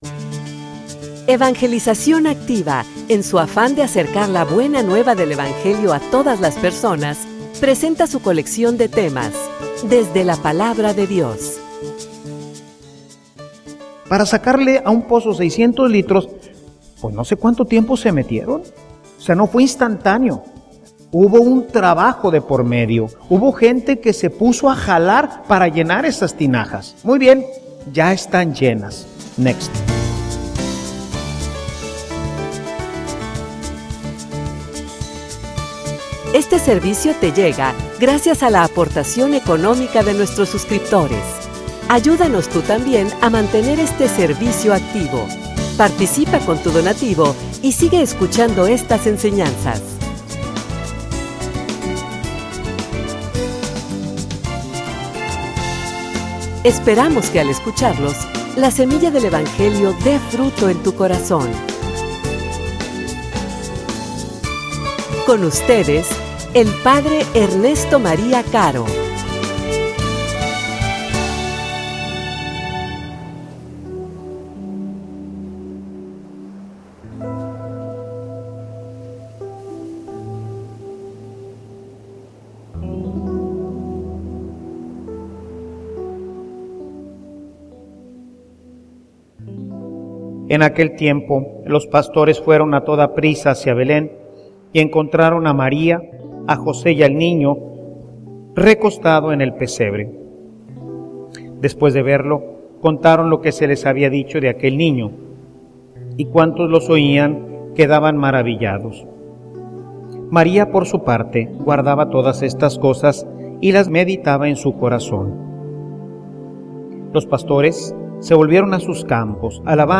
homilia_Poderosa_intercesora.mp3